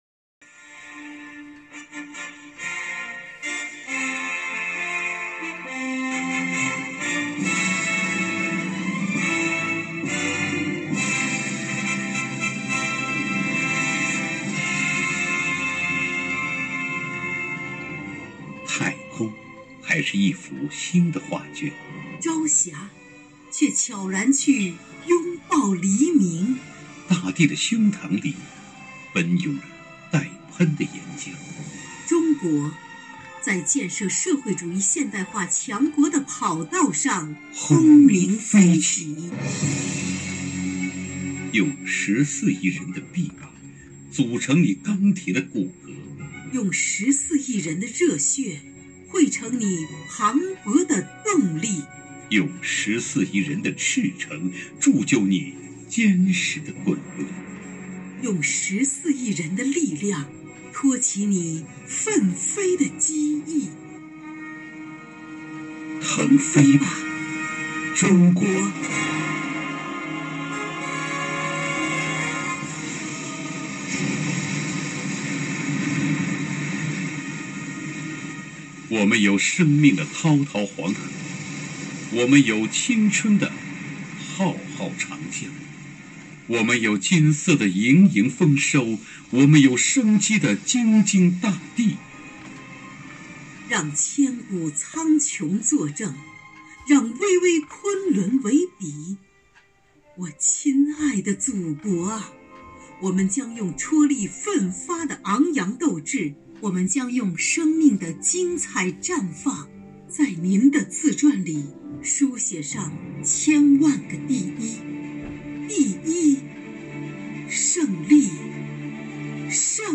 朗诵：腾飞吧，中国